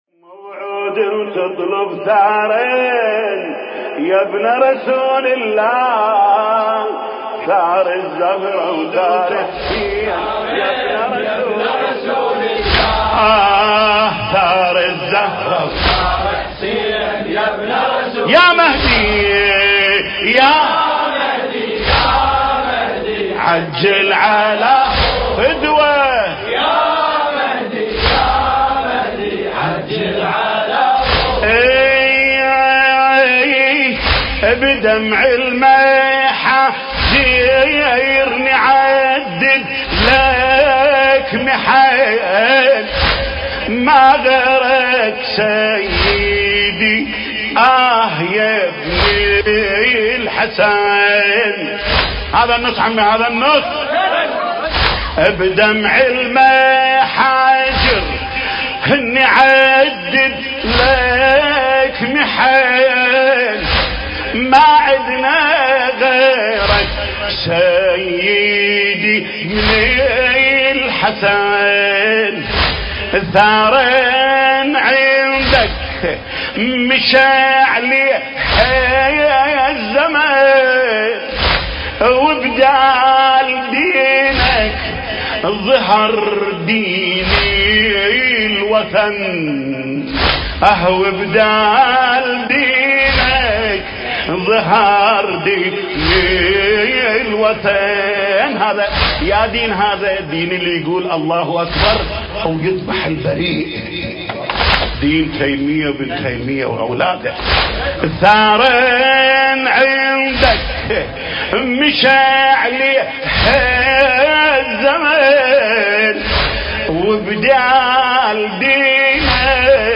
المكان: حسينية ابن الرضا الإمام محمد الجواد (عليه السلام) – استراليا – سدني